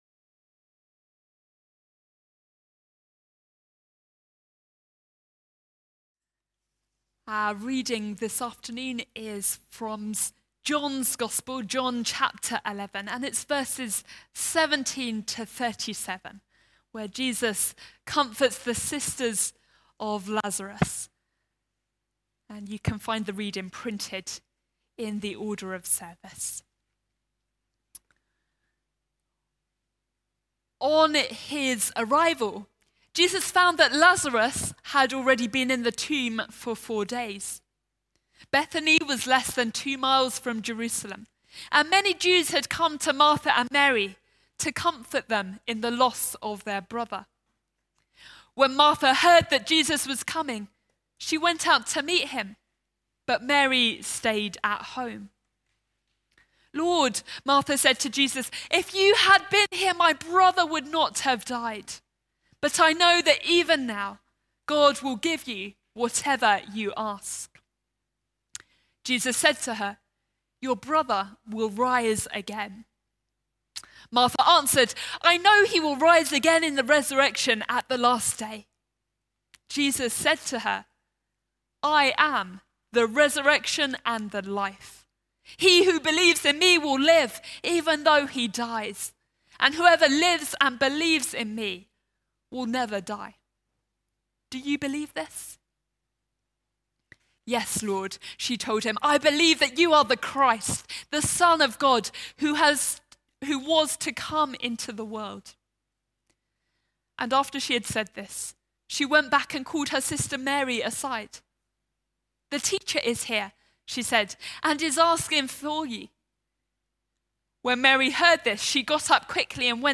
John 11:17-37 – Annual Memorial Service
Series: Stand-alone sermons